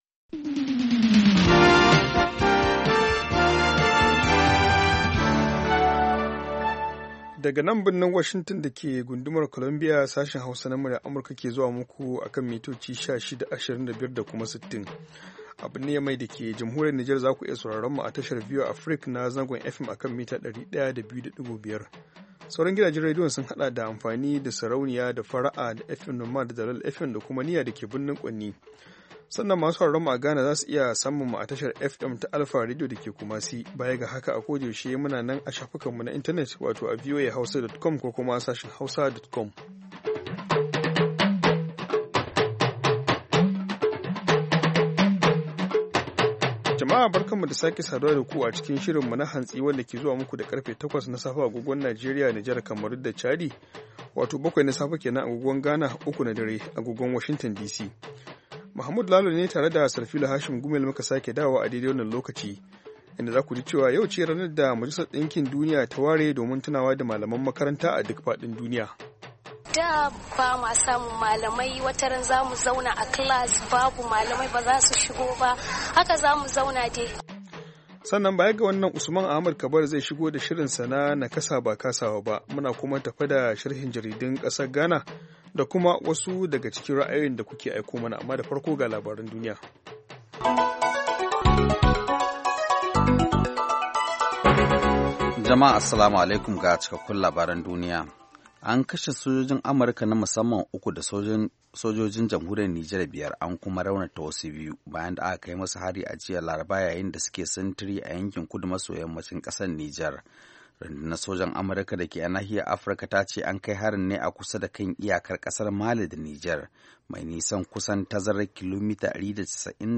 Mu kan komo da karfe 8 na safe agogon Najeriya da Nijar domin sake gabatar muku da labarai da hirarraki, da sharhin jaridu kama daga Najeriya zuwa Nijar har Ghana, da kuma ra’ayoyinku.